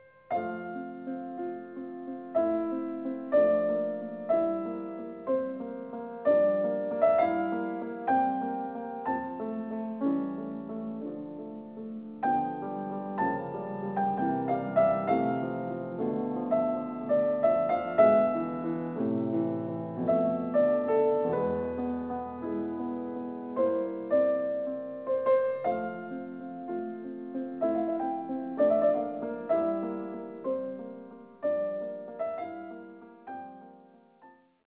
Piano encores